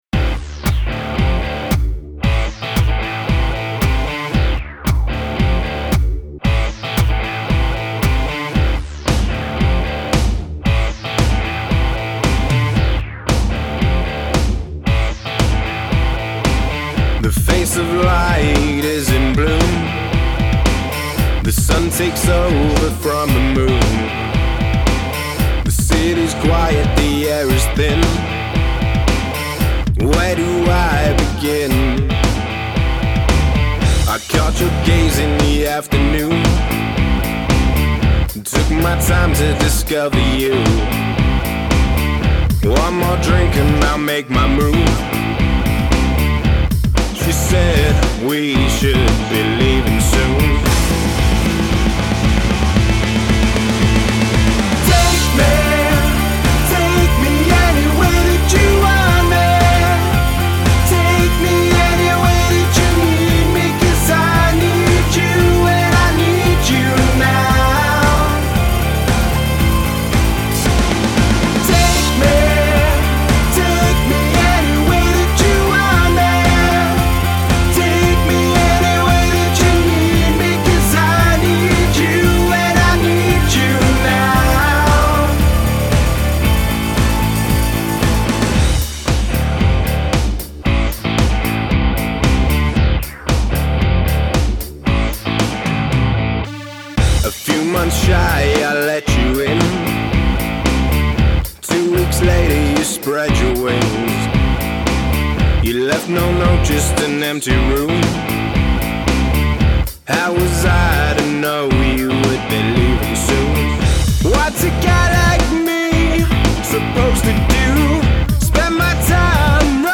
Male Vocal, Electric Guitar, Synth, Bass Guitar, Drums